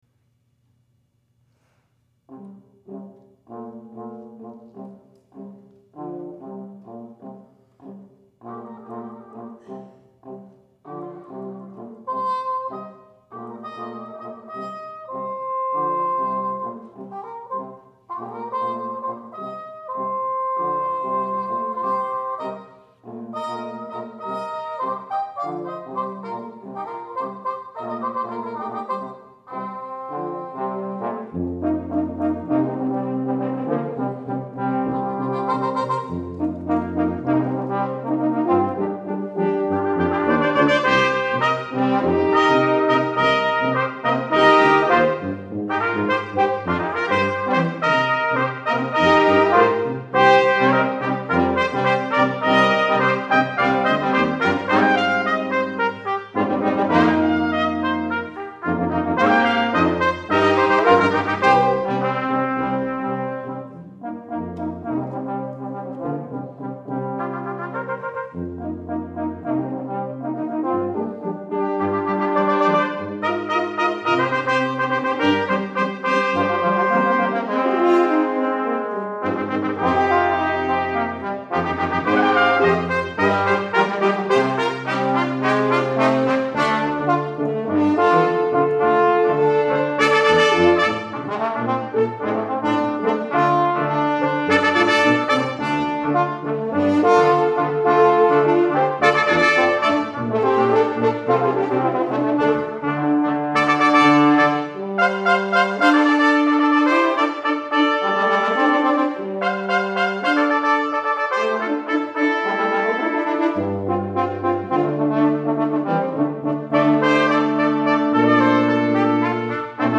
for Brass Quintet (2000)
" is intended to be a short burst of joy and energy.